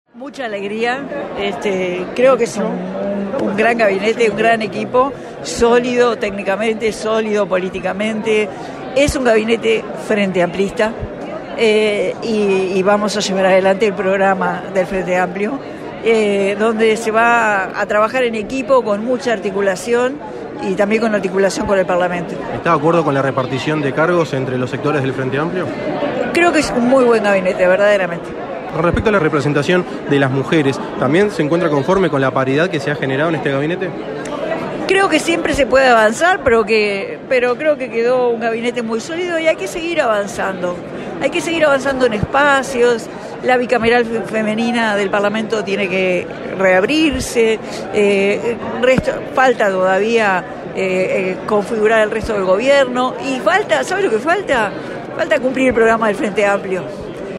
La vicepresidenta electa, Carolina Cosse, en diálogo con 970 Noticias tras la presentación del gabinete para el próximo período de gobierno, se refirió a la designación de las mujeres que forman parte del gobierno de Yamandú Orsi.